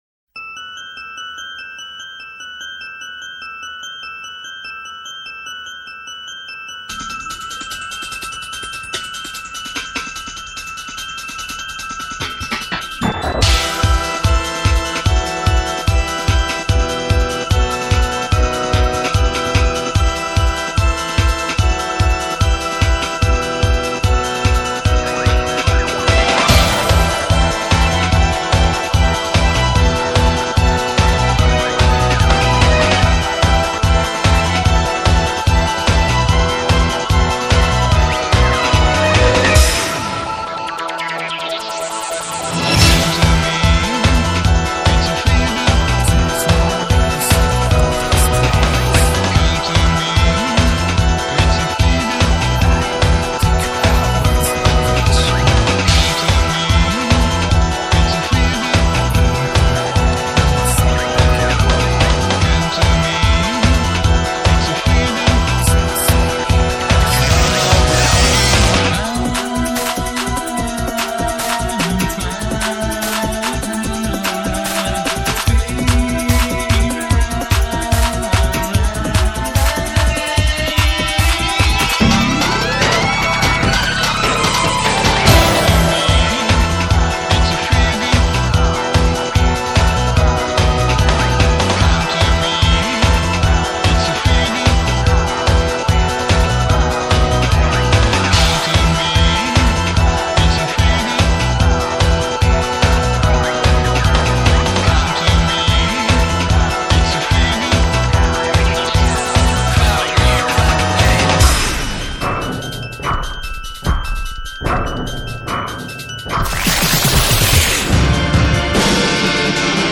Стиль: Visual Kei.